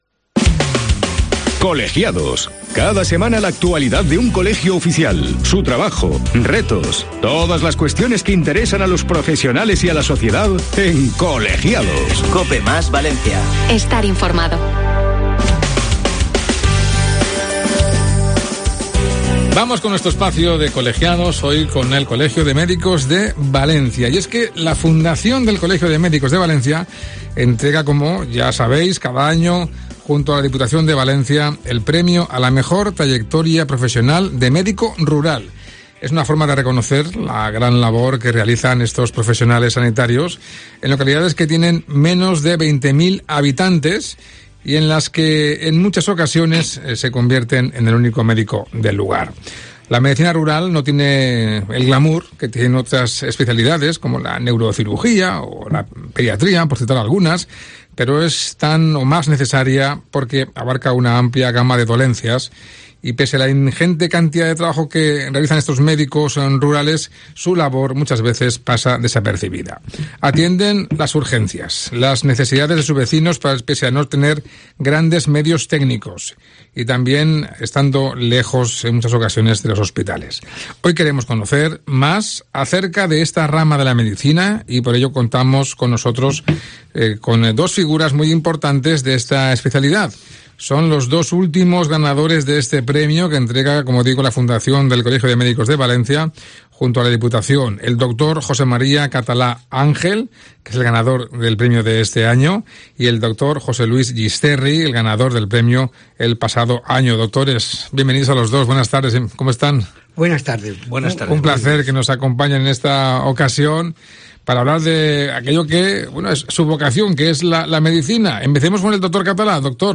Hoy en Cope Valencia conocemos más acerca de esta rama de la Medicina contando en directo con dos figuras muy importantes de esta especialidad.